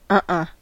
Ääntäminen
Vaihtoehtoiset kirjoitusmuodot uh uh uhuh huh-uh unh-uh Ääntäminen US : IPA : [ʌ̃˧.ʔʌ̃˧] Tuntematon aksentti: IPA : [ˈ(ʔ)ʌ̃.ʔʌ̃] Haettu sana löytyi näillä lähdekielillä: englanti Käännöksiä ei löytynyt valitulle kohdekielelle.